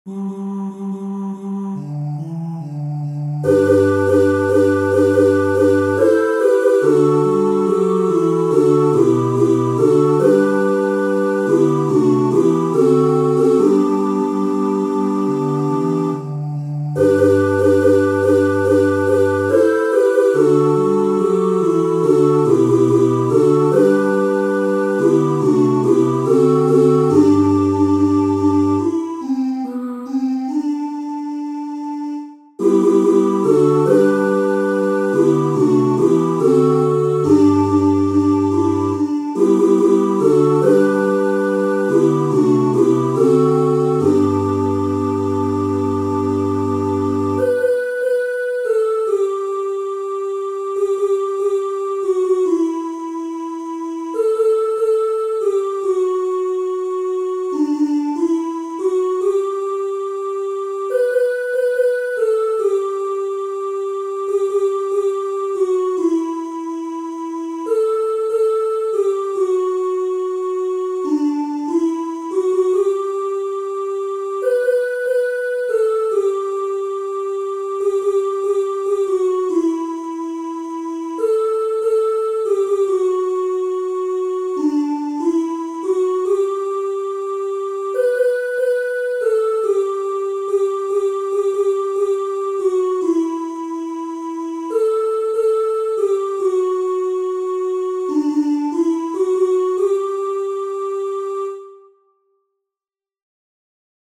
• Catégorie : Chants de l’Avent